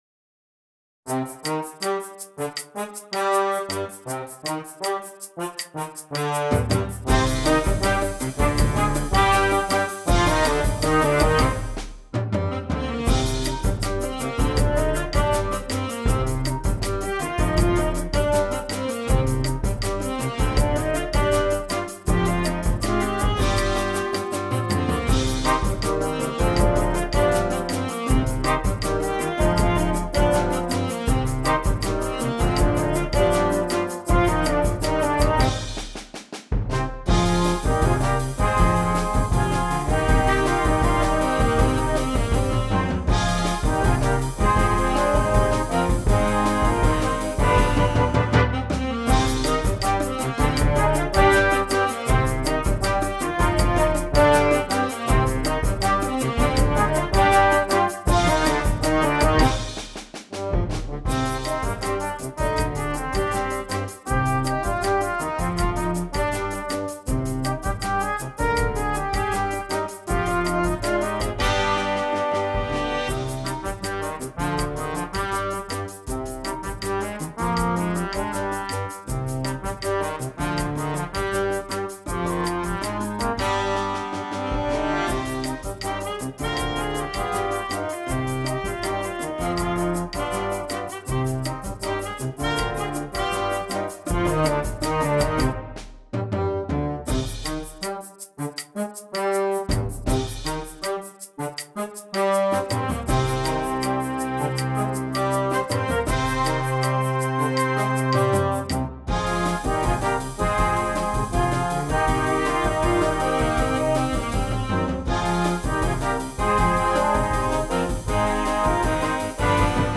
Concert Band
There are written out solos included for several parts.